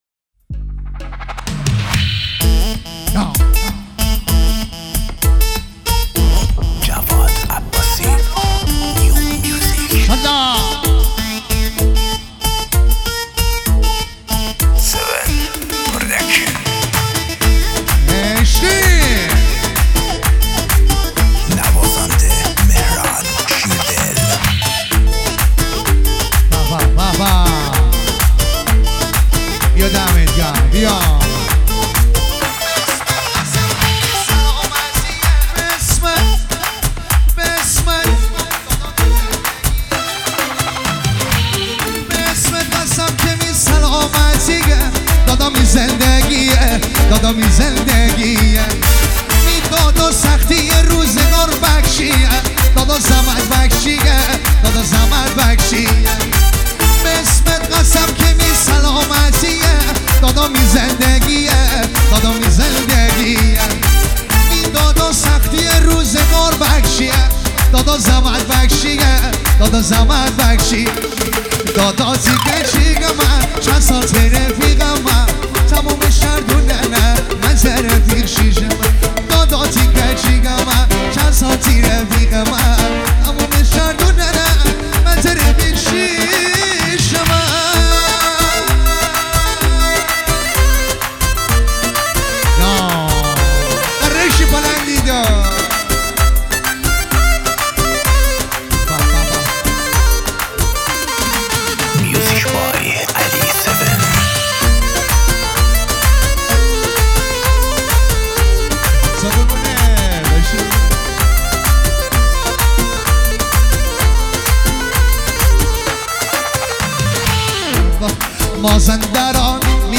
جشنی
آهنگی در سبک آهنگ های جشنی مازندرانی